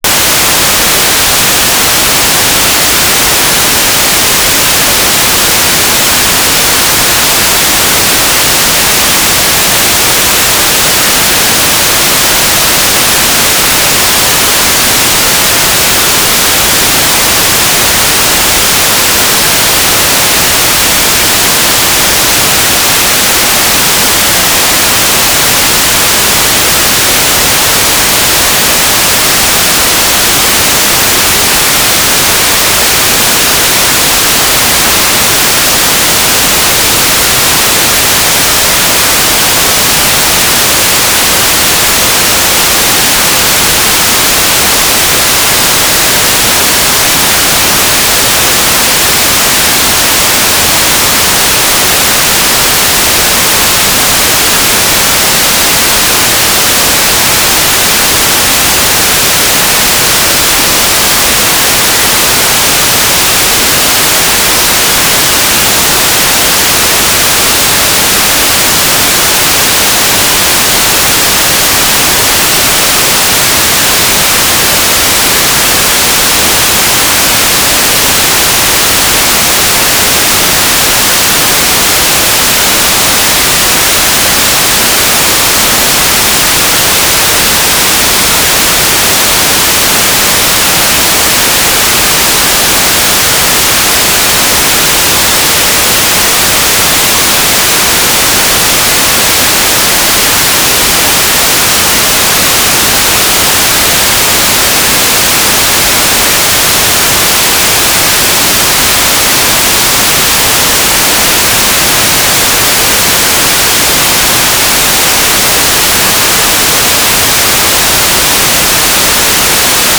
"transmitter_mode": "FM",